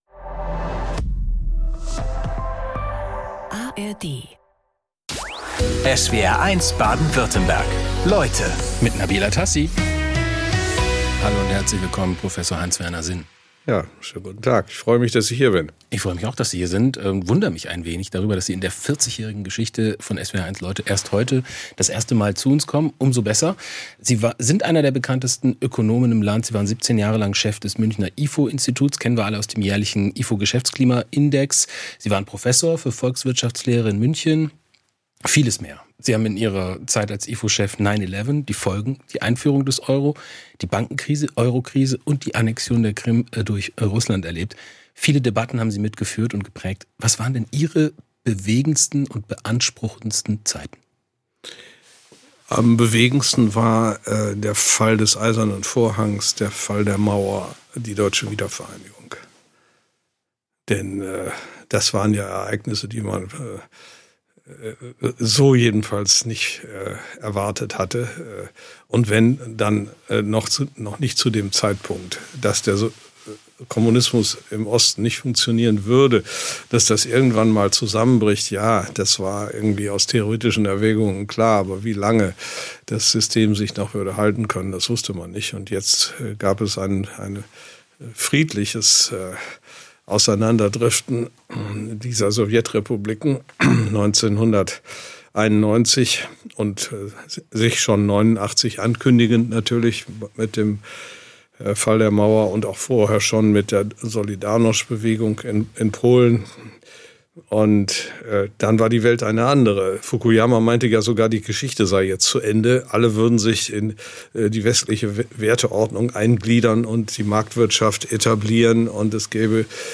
Wie steht es um den Wirtschaftsstandort Deutschland? Und was können der Staat und die Bürger tun? Fragen an den Wirtschaftswissenschaftler Hans-Werner Sinn.